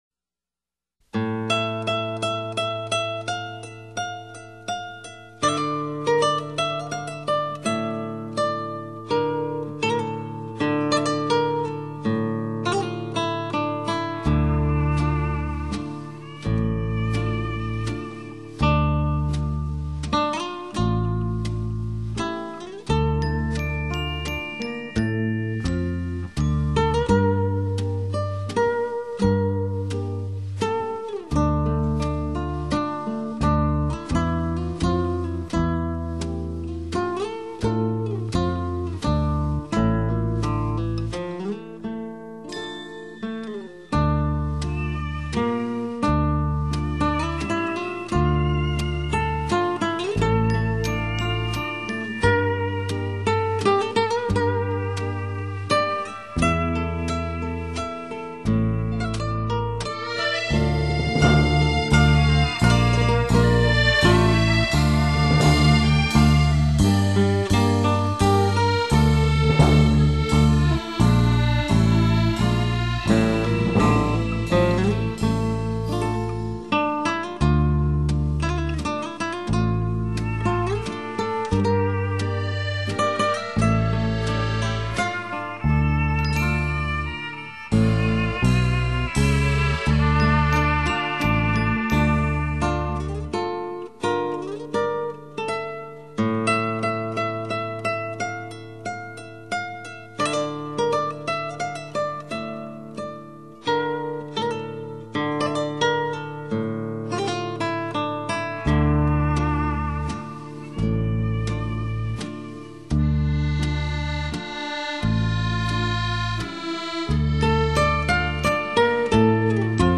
这些经典金曲给你带来无限思念，段段回忆，上好音色，动听旋律，节奏明快，意境浪漫，情怀醉心宝典。